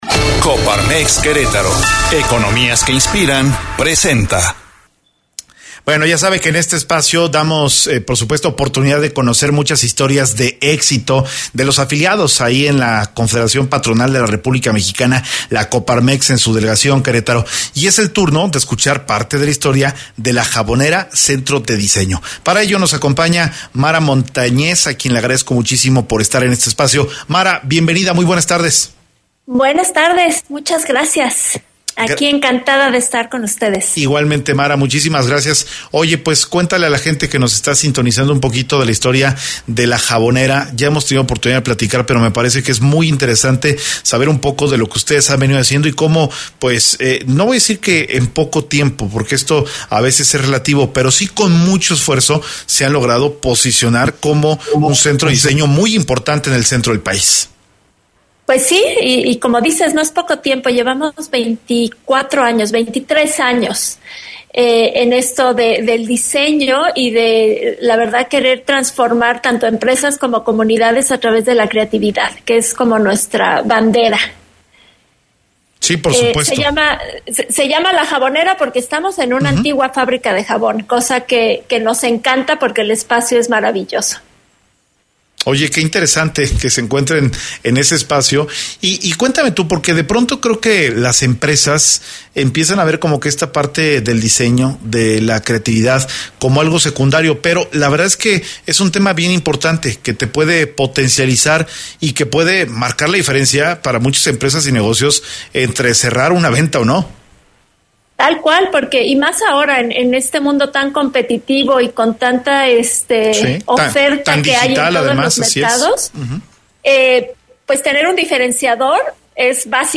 EntrevistasMunicipiosPodcastU2